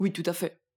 VO_ALL_Interjection_16.ogg